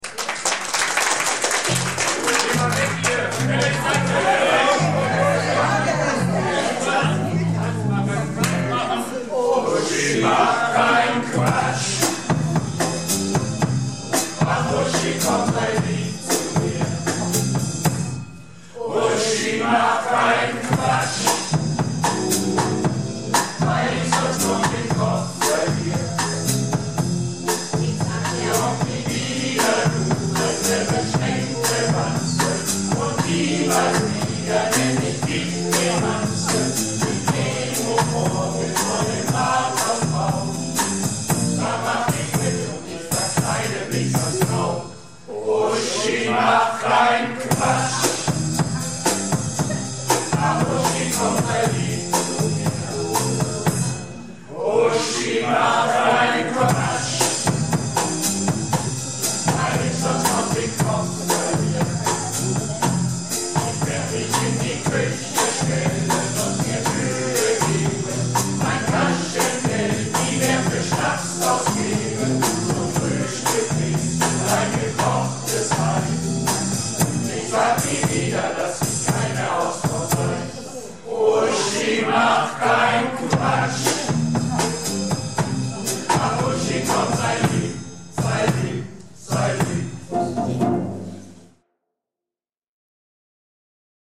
Projektchor "Keine Wahl ist keine Wahl" - Theaterprobe 28.09.19